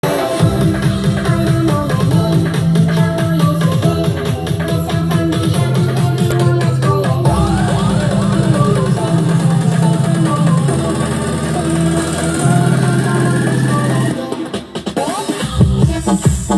1 utwór, proszę o pomoc w znalezieniu tytułu - Muzyka elektroniczna